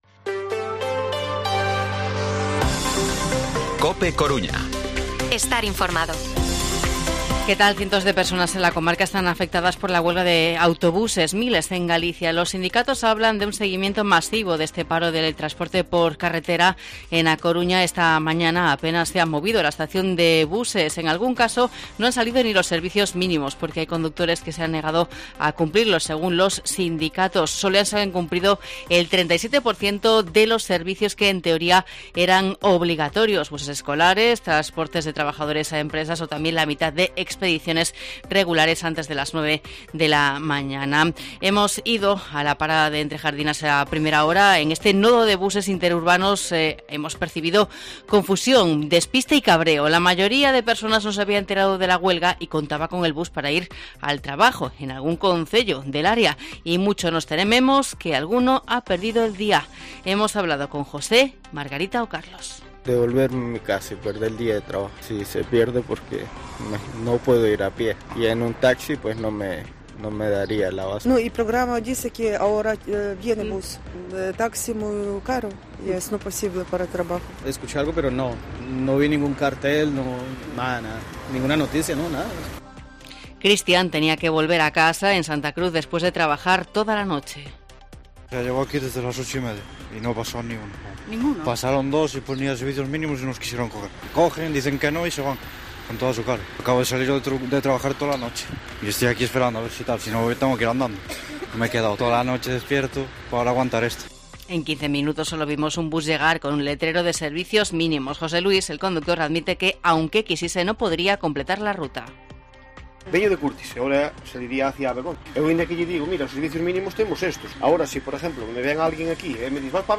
Informativo Mediodía COPE Coruña viernes, 31 de marzo de 2023 14:20-14:30